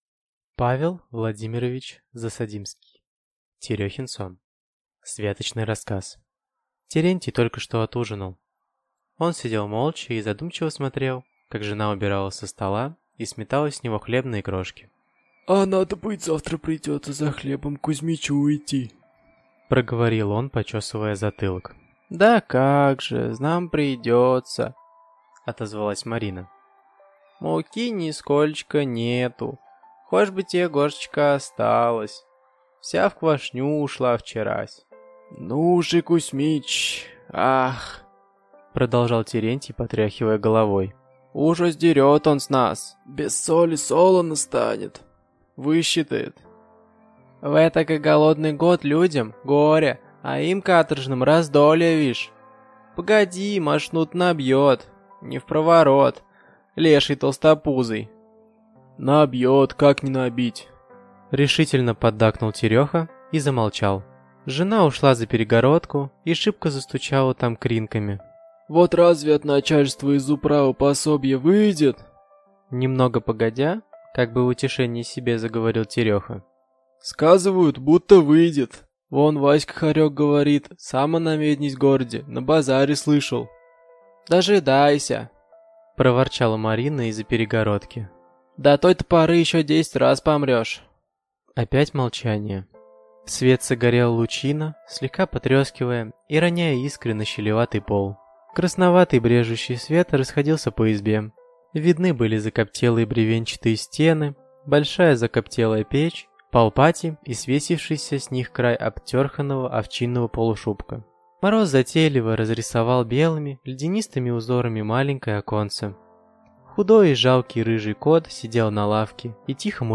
Аудиокнига Терехин сон | Библиотека аудиокниг